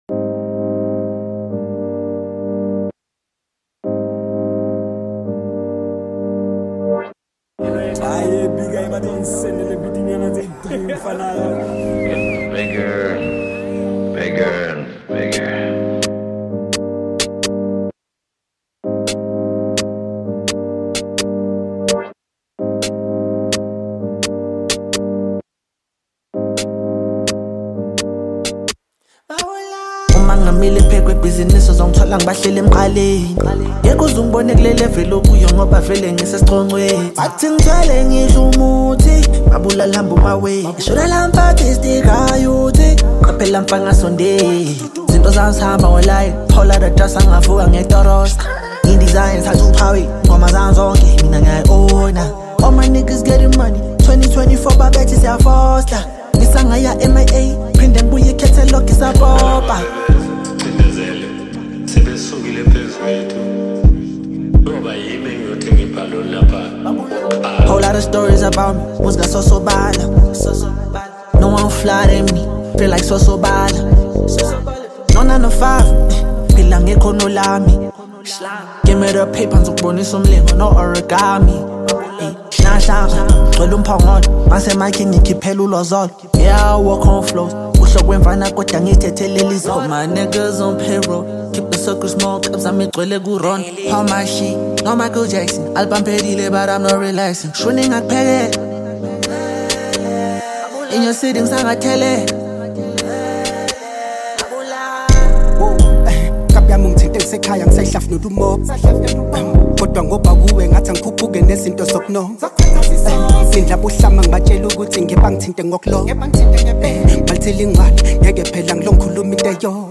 Amapiano, Hip Hop